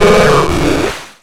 Cri de Magicarpe dans Pokémon X et Y.